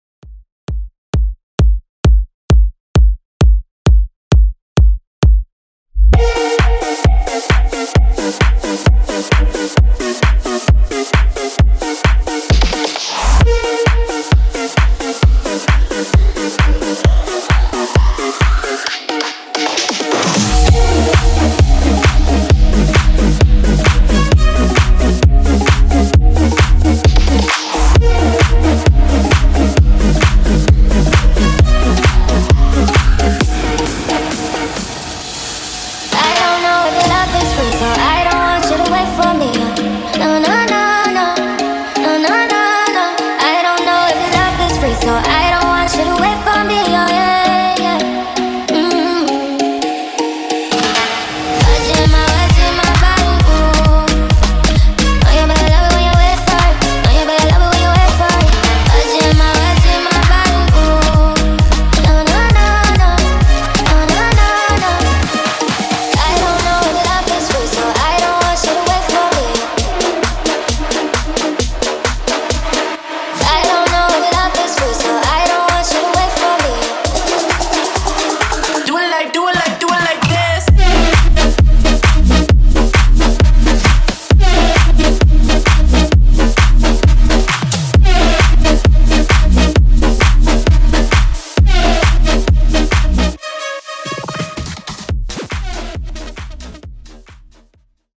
In-Outro House)Date Added